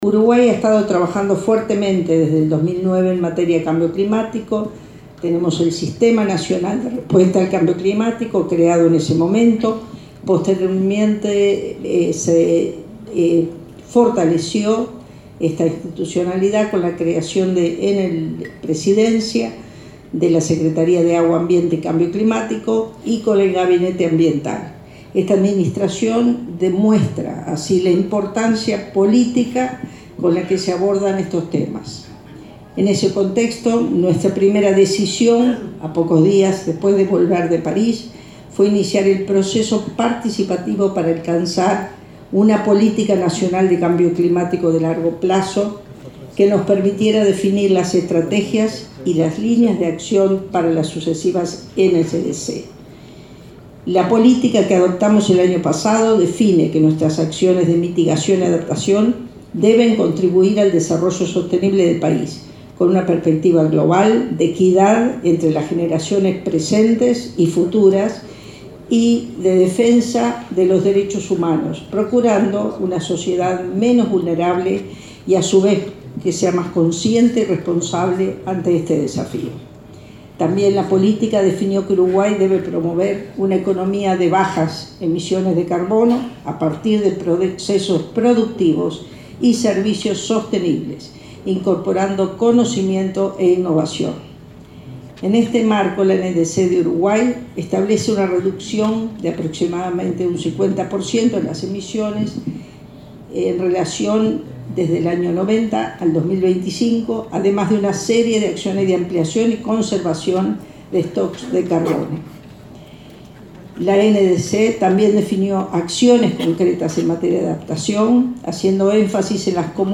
Desde 2009 Uruguay promueve una política que apueste a una economía de bajas emisiones de carbono a partir de procesos productivos y servicios sostenibles, incorporando conocimiento e innovación, dijo la titular de Medio Ambiente, Eneida de León, en la inauguración del Segmento de Alto Nivel de la Semana del Clima.